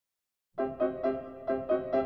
0239-钢琴名曲乳母的歌.mp3